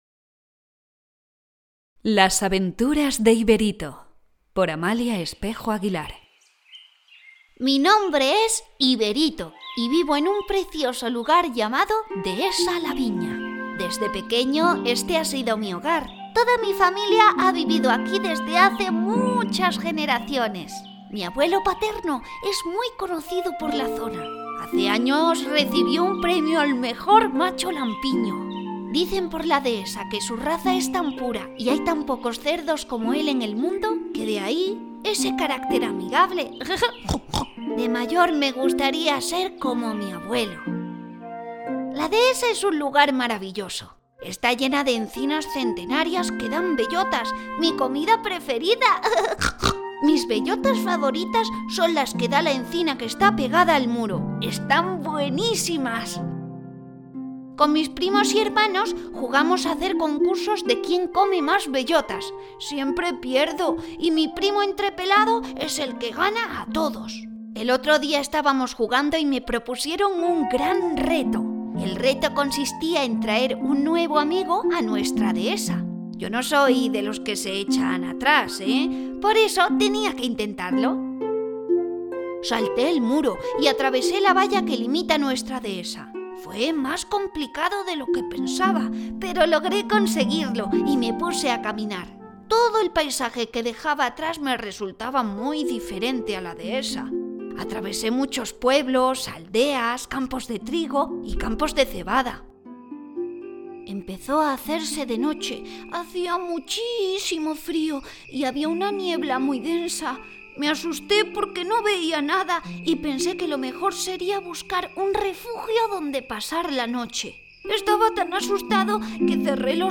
Audiolibro: Las aventuras de Iberito
IBERITO-AUDIOCUENTO-FINAL.mp3